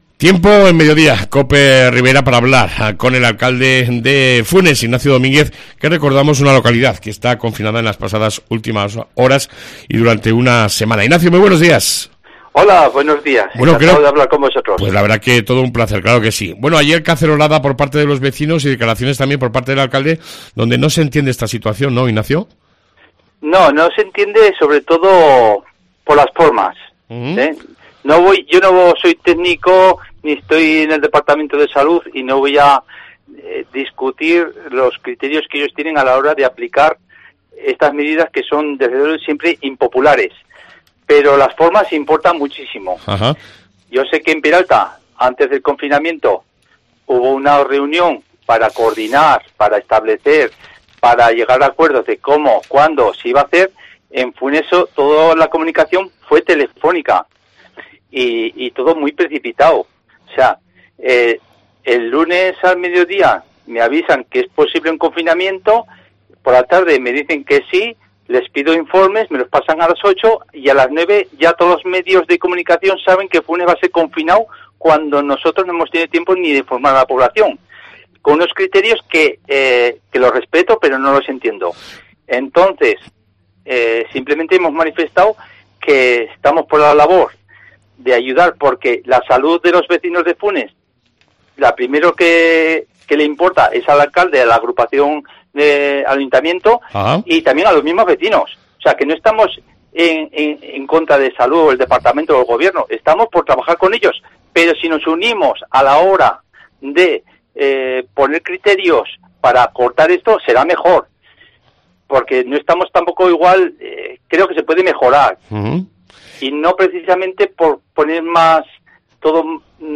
AUDIO: Entrevista con Ignacio Domínguez Alcalde de Funes